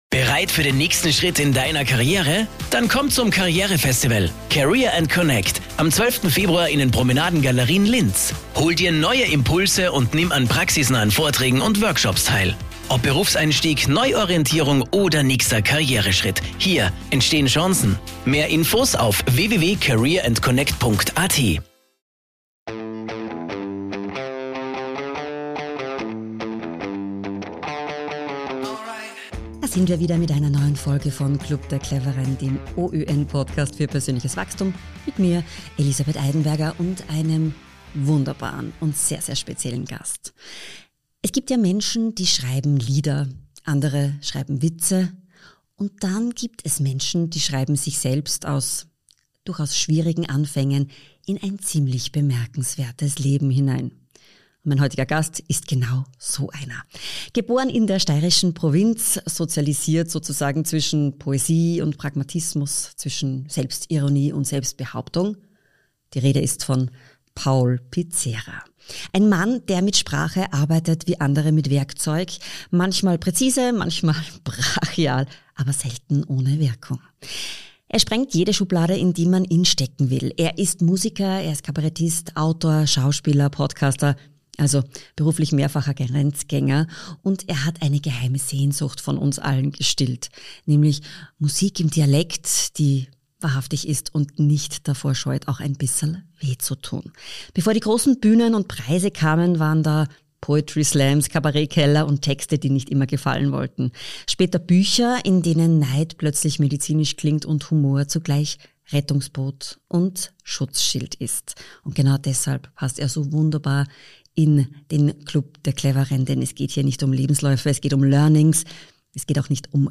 In der neuen Folge spricht Musiker Paul Pizzera über ständiges Lernen, das Alleinsein und den Spagat zwischen Applaus und echtem Selbstwert.